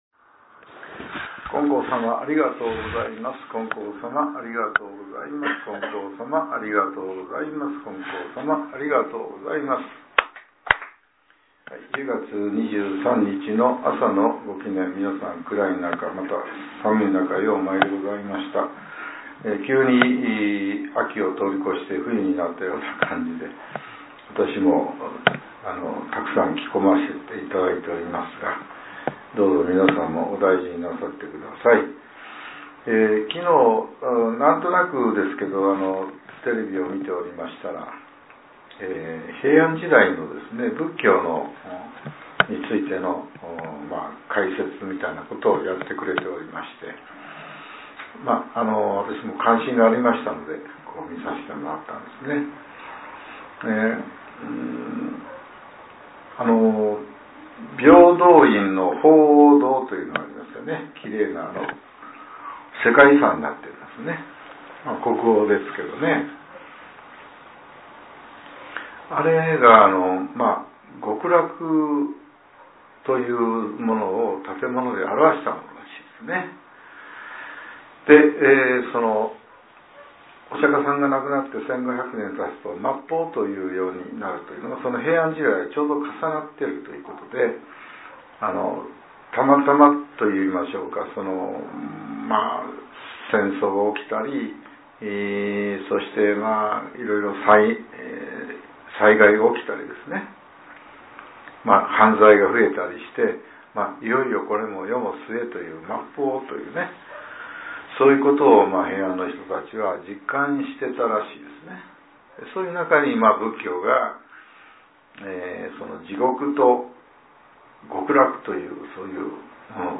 令和７年１０月２３日（朝）のお話が、音声ブログとして更新させれています。